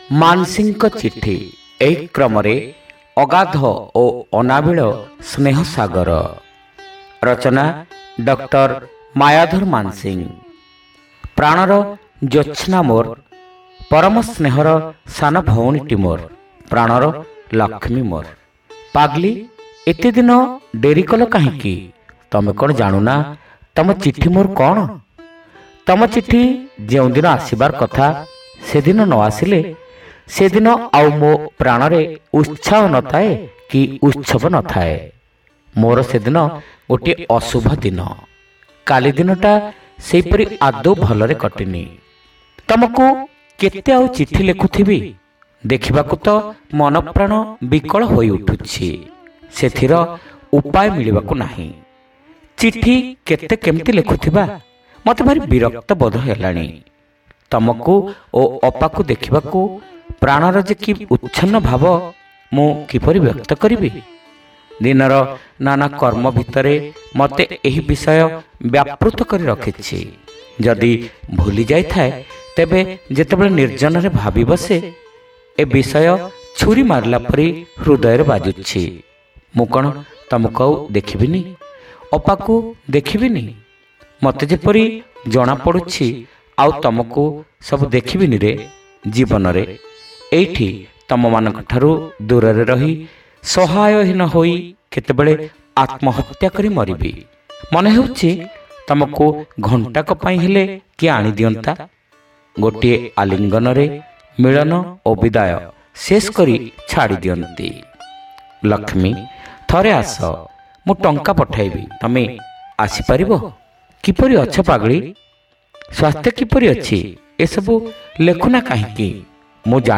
ଶ୍ରାବ୍ୟ ଗଳ୍ପ : ଅଗାଧ ଓ ଅନାବିଳ ସ୍ନେହ ସାଗର